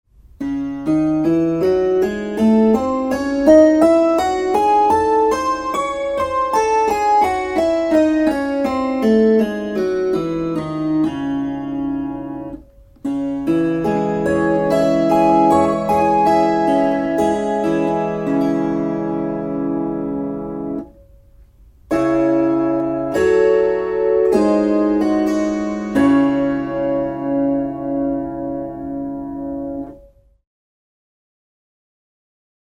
Kuuntele cis-molli. his gis fis dis cis Opettele duurit C G D A E F B Es As mollit a e h fis d g c f Tästä pääset harjoittelun etusivulle.